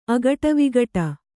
♪ agaṭavigaṭa